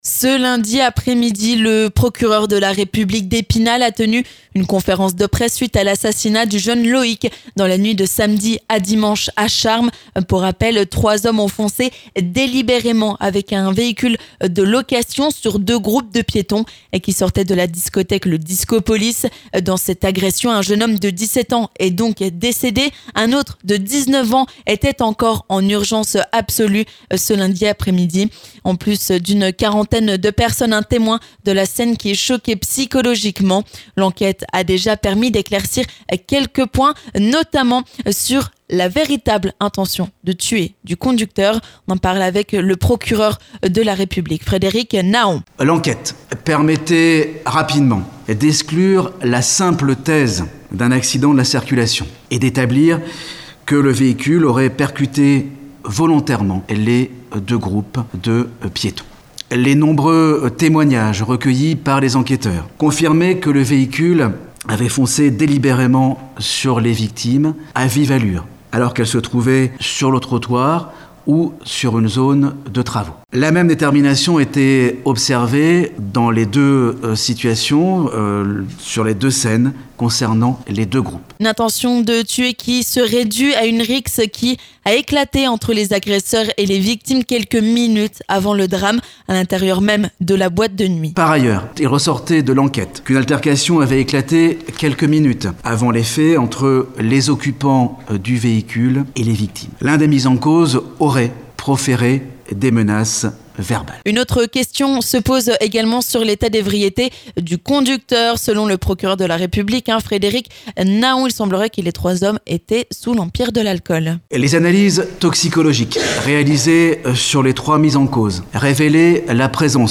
Quelques jours après ce drame, nous sommes allés à la rencontre du maire de Charmes, Patrick Boeuf. Il pointe du doigt la responsabilité de la discothèque sur la consommation d'alcool du conducteur et demande une fermeture temporaire.